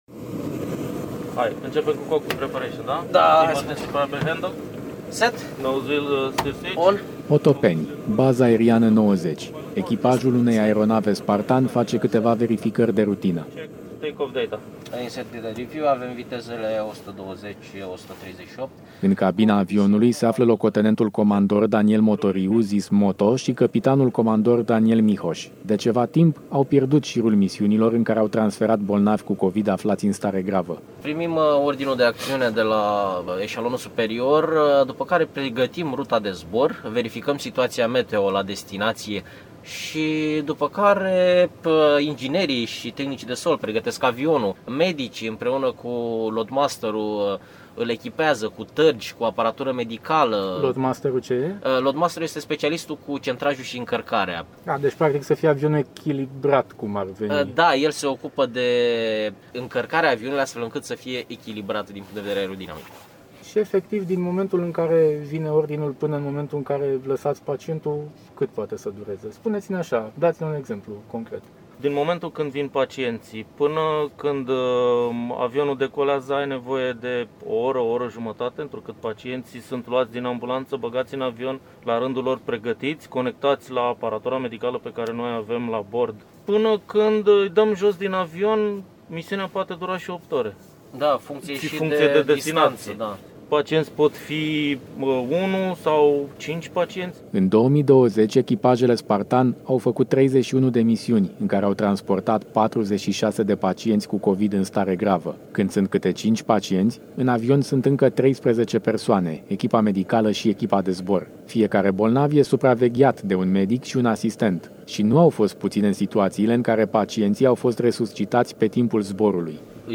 LUMEA EUROPA FM: Secțiile ATI cu aripi | REPORTAJ